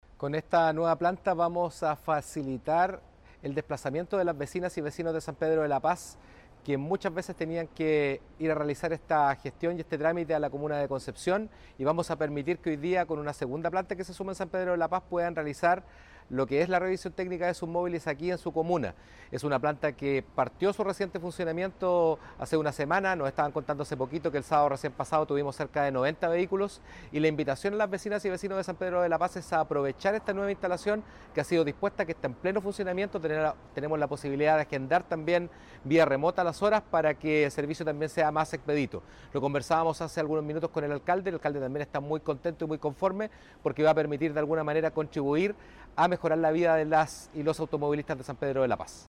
Por su parte, el seremi de Transportes abordó los beneficios que traerá para las y los sanpedrinos esta nueva planta.